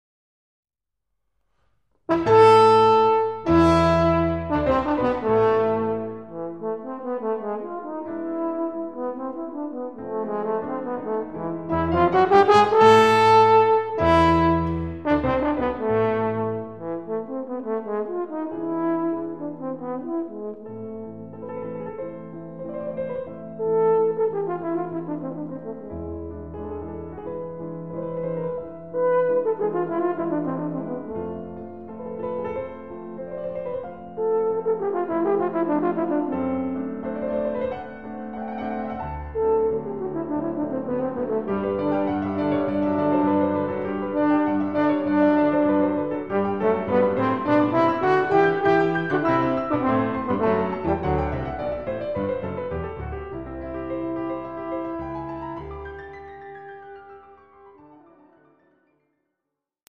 Voicing: Horn and Piano